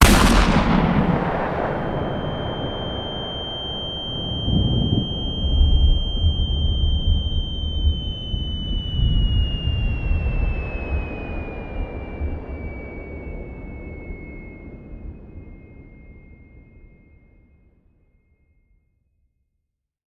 shotTinnitus.ogg